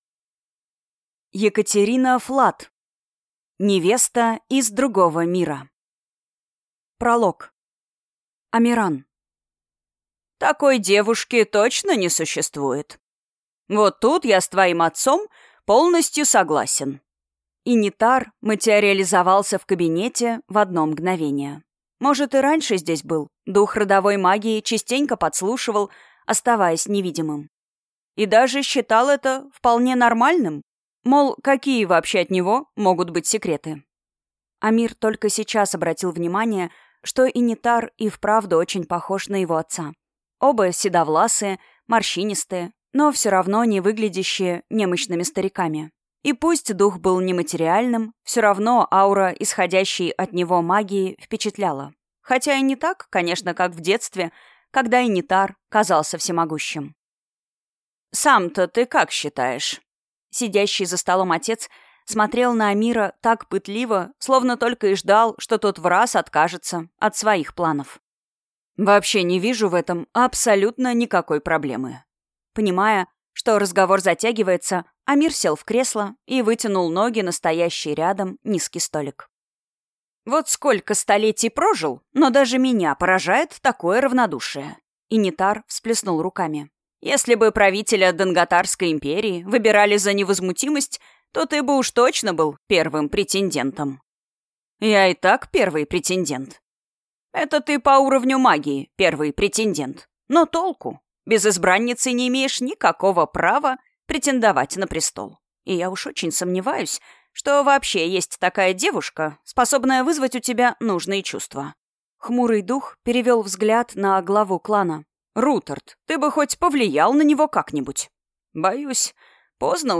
Аудиокнига Невеста из другого мира | Библиотека аудиокниг
Прослушать и бесплатно скачать фрагмент аудиокниги